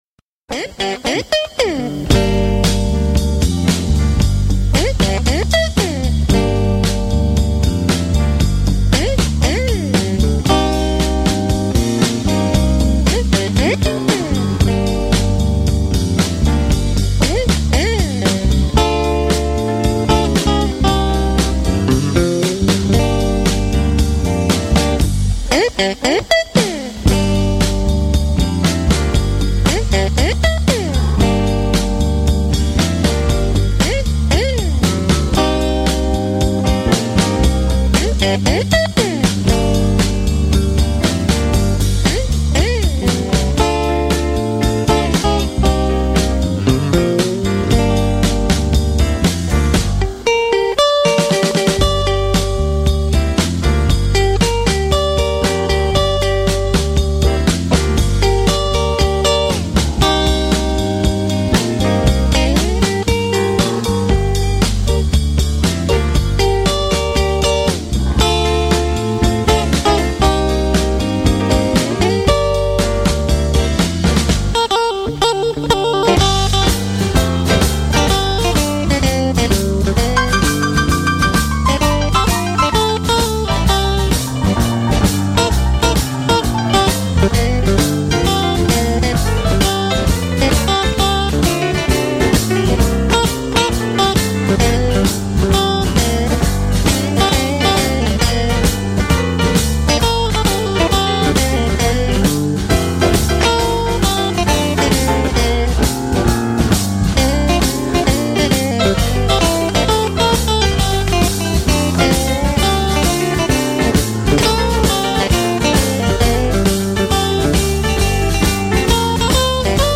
Может кому то известна эта интересная блюзовая композиция?
Инструментал  Блюз.mp3